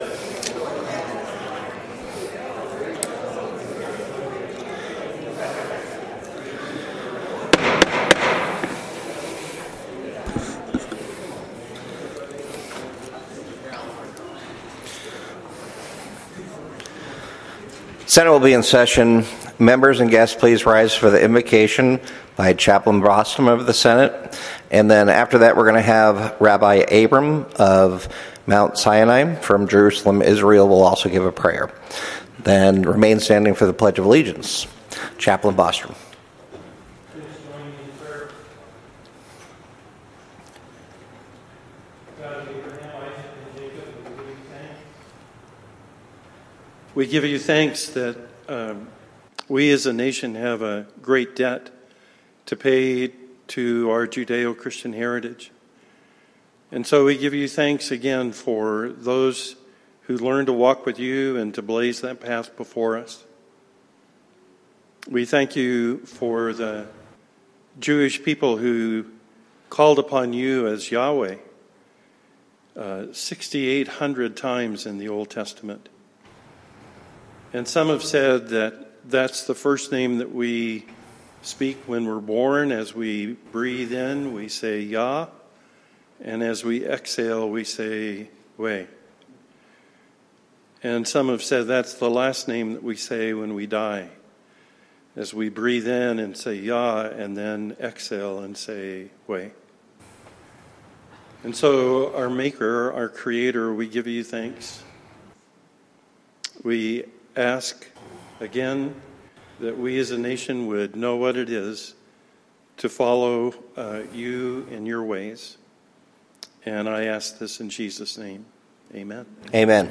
Senate Floor Session